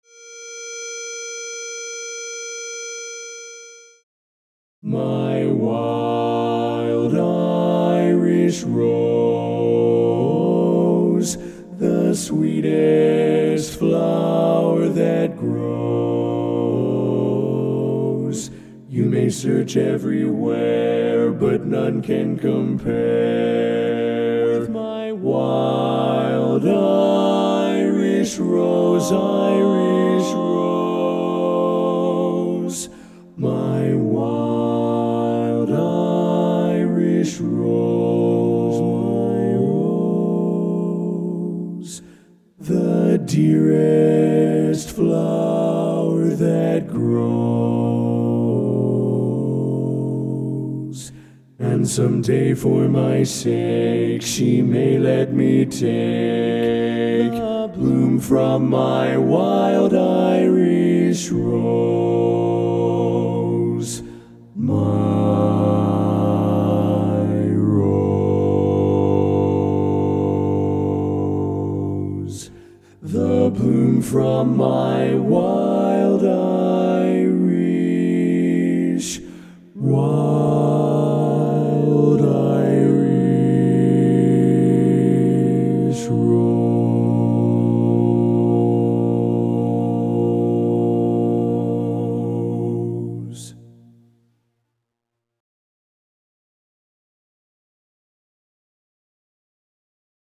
Kanawha Kordsmen (chorus)
Ballad
B♭ Major
Bass